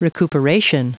Prononciation du mot recuperation en anglais (fichier audio)
Prononciation du mot : recuperation